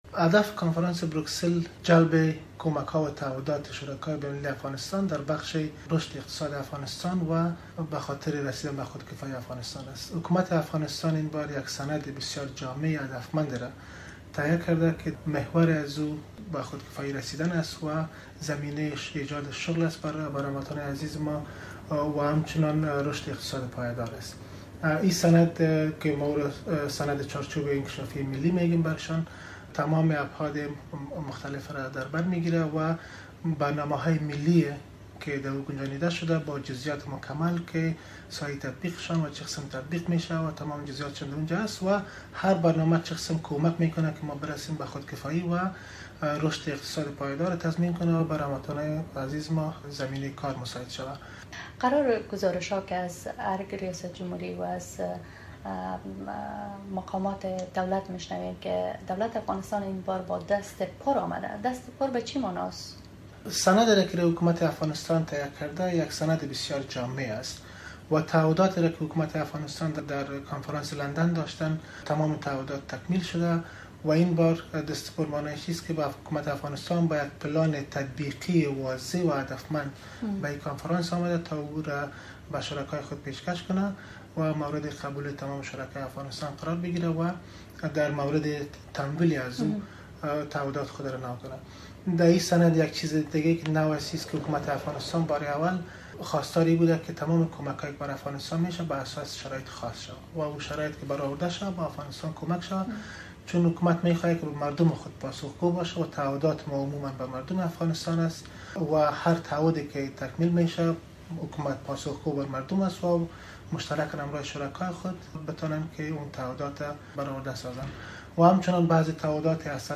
مصاحبه ها
مصاحبه با هارون چخانسوری، سخنگوی ریاست جمهوری افغانستان